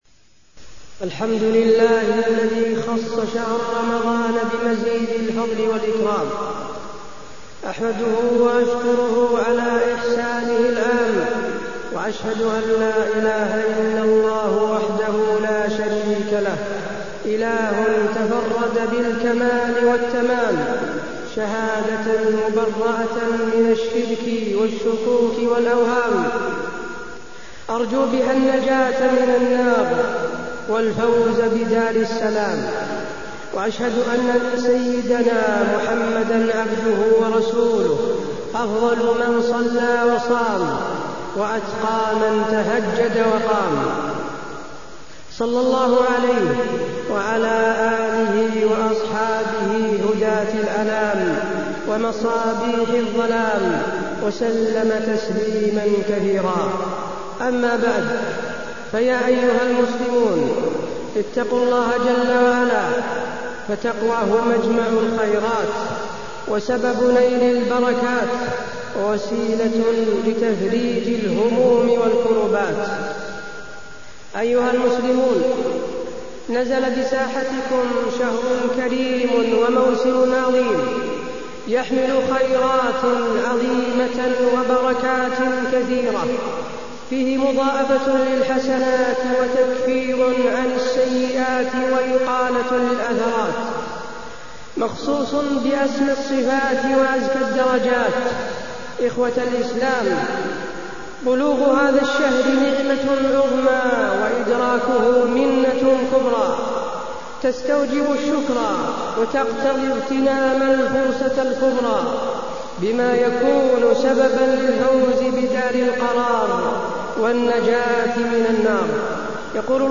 تاريخ النشر ١ رمضان ١٤٢٢ هـ المكان: المسجد النبوي الشيخ: فضيلة الشيخ د. حسين بن عبدالعزيز آل الشيخ فضيلة الشيخ د. حسين بن عبدالعزيز آل الشيخ استقبال رمضان